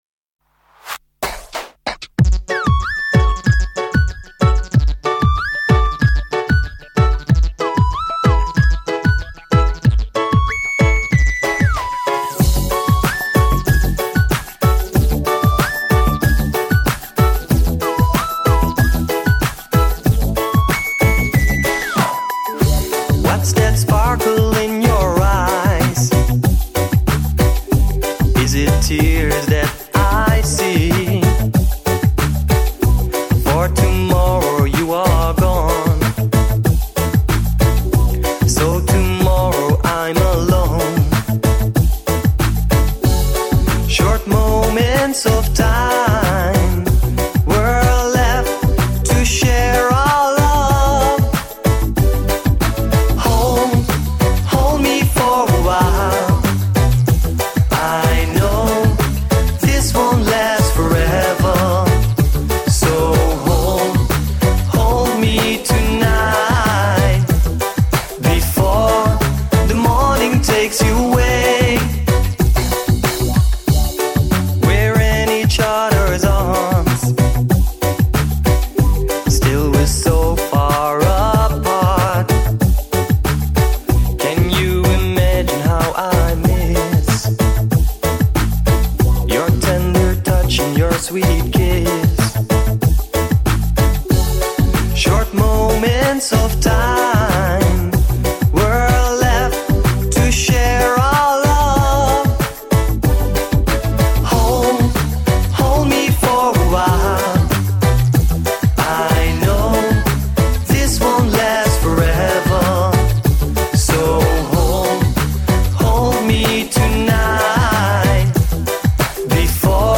Только у него в стиле "еврореггей", а у шведов - баллада.